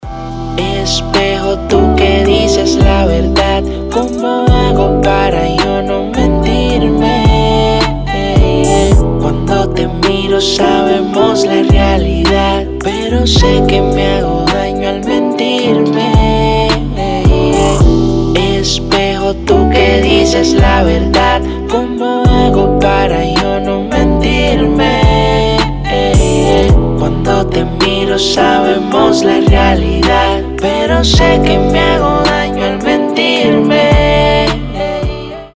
• Качество: 320, Stereo
Хип-хоп
спокойные
приятные
Latin Pop